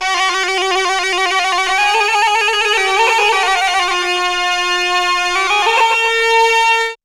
AFGANPIPE2-R.wav